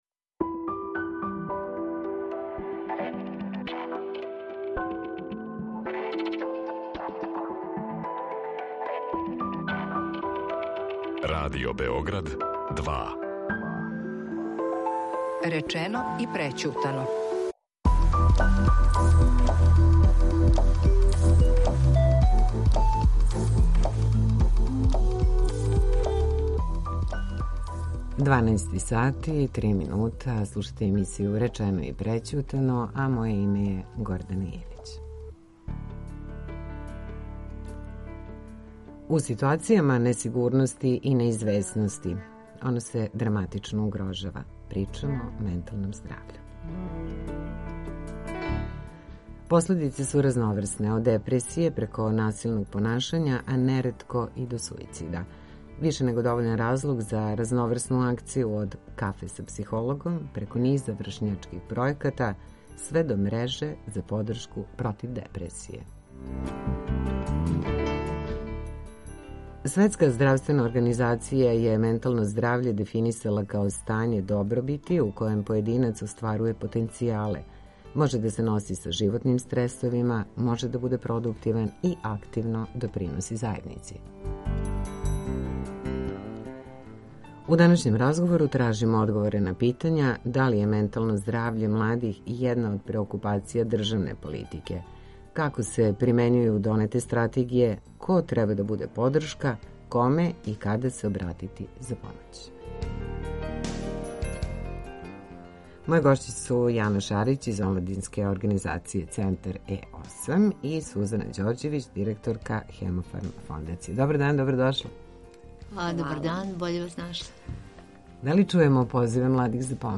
У данашњем разговору тражимо одговоре на питања: да ли је ментално здравље младих једна од преокупација државне политике, како се примењују донете стратегије, ко треба да буде подршка, коме и када се обратити за помоћ.